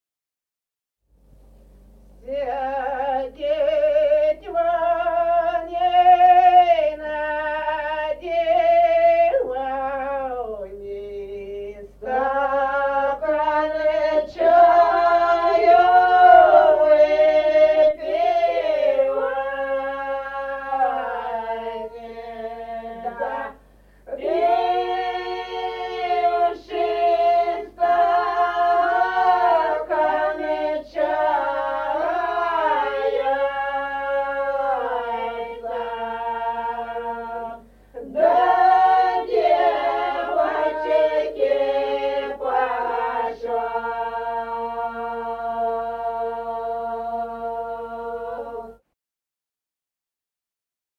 Песни села Остроглядово.